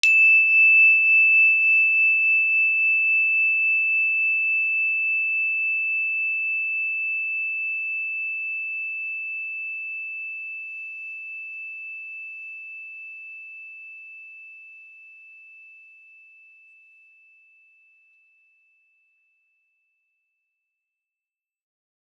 energychime_wood-E6-mf.wav